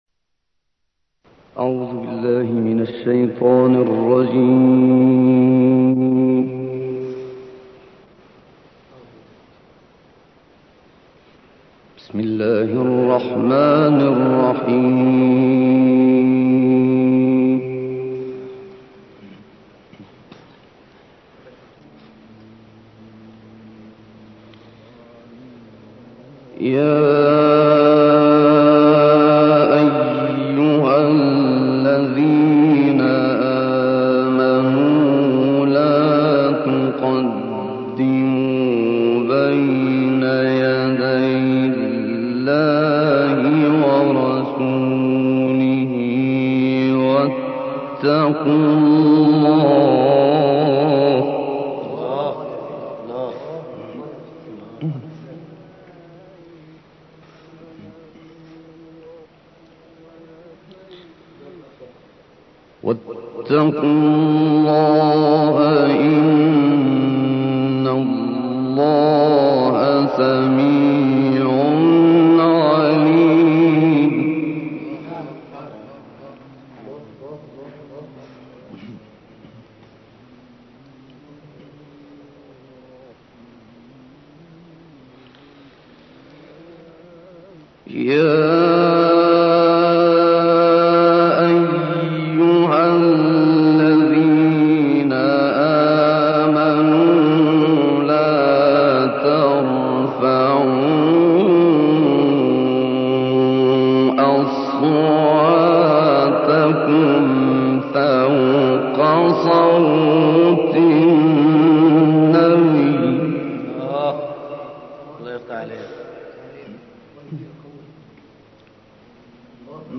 دانلود قرائت سوره های حجرات و ق آیات 1 تا 18